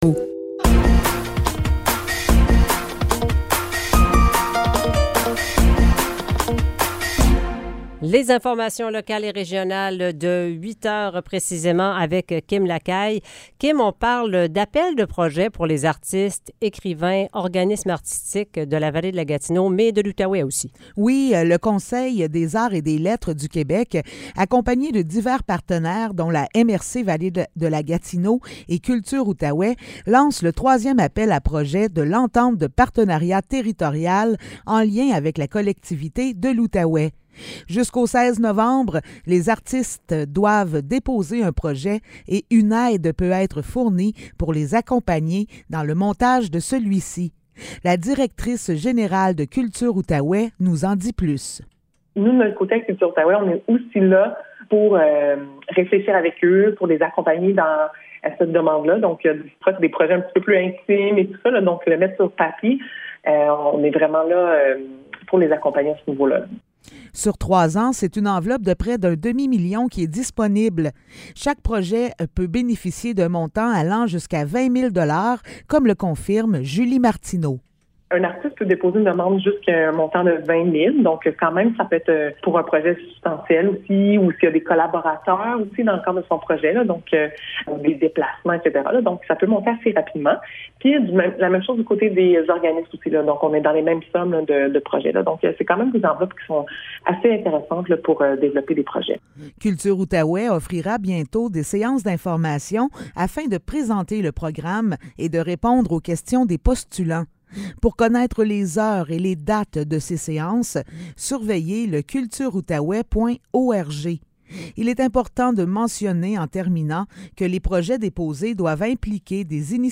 Nouvelles locales - 6 septembre 2023 - 8 h